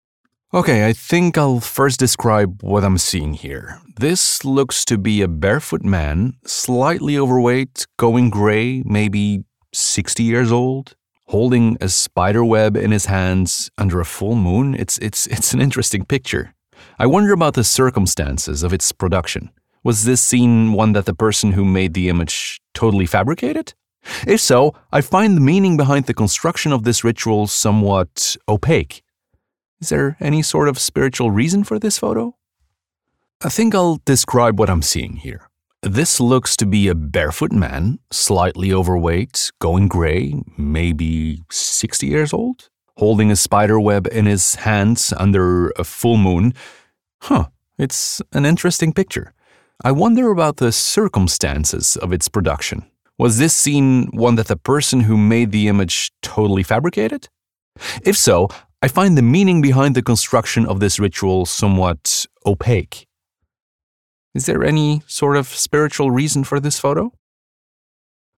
Englisch (International)
Cool, Vielseitig, Zuverlässig, Tief, Natürlich
Persönlichkeiten